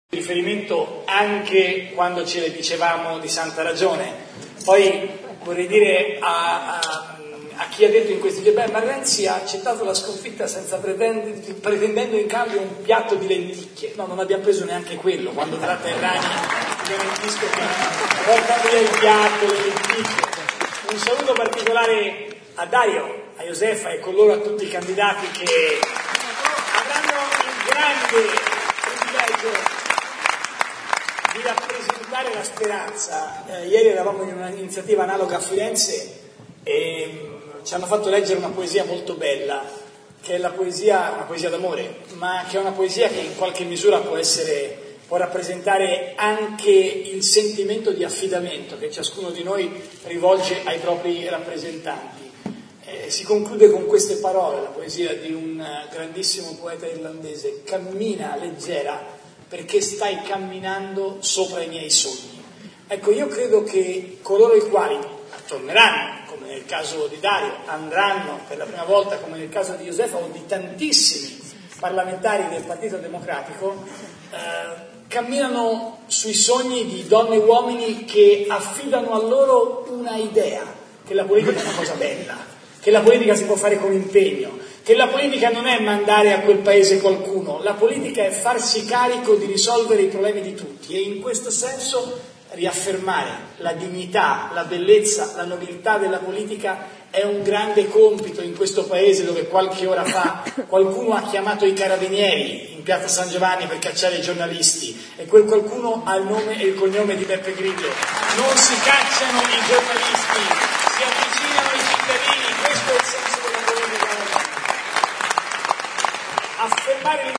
ecco un passaggio del suo intervento dal palco dell’Arena